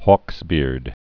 (hôksbîrd)